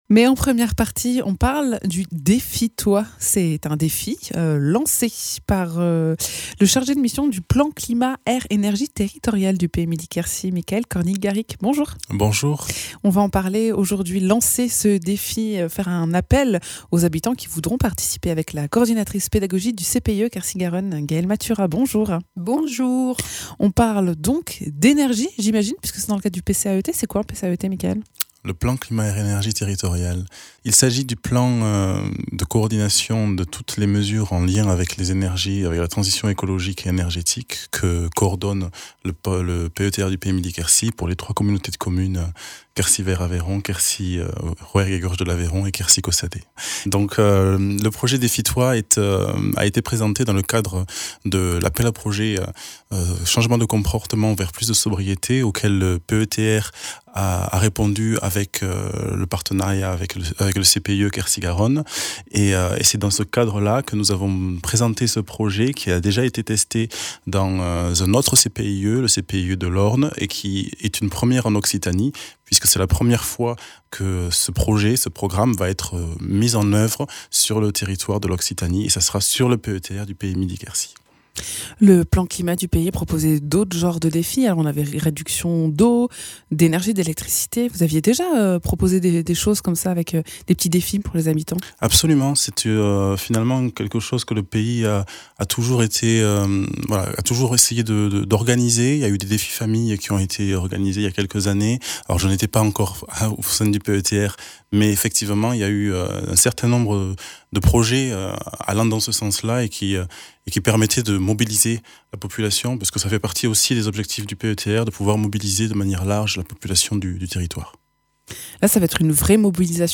Invité(s)